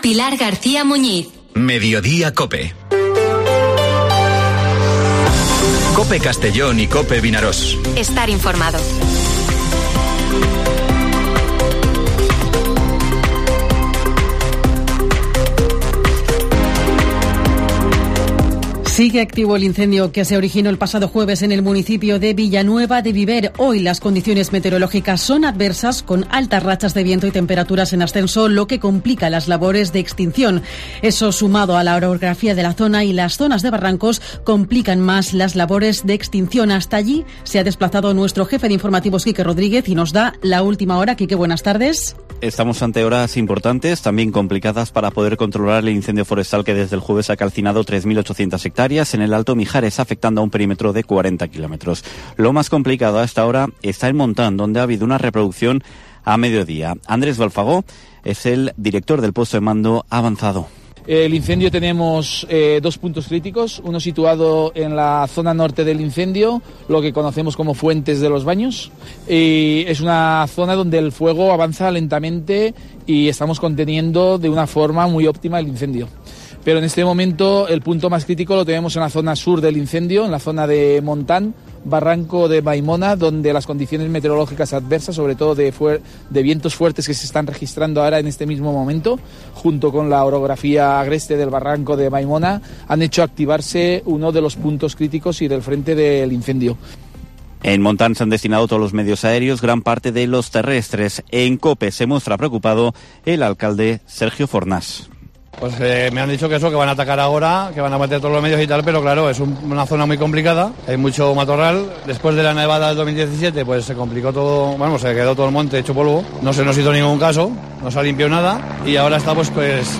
Informativo Mediodía COPE en la provincia de Castellón (27/03/2023)